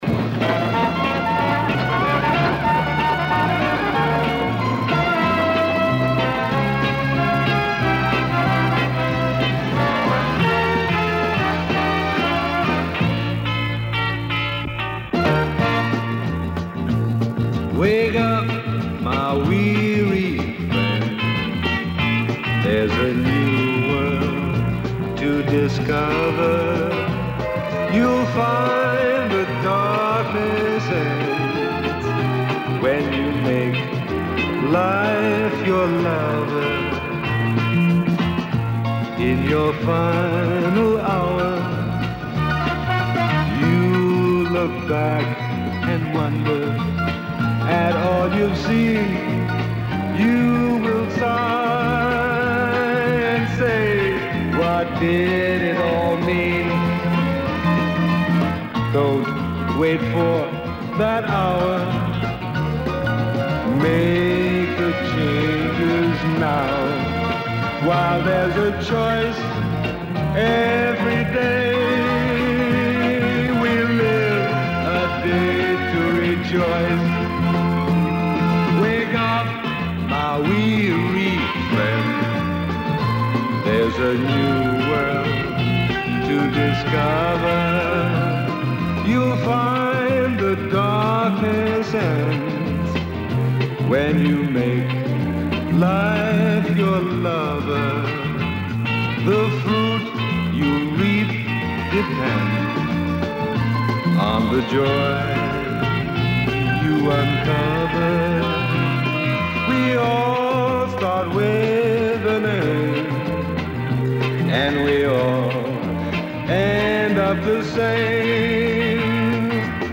Прилагаю фоно на выходных титрах.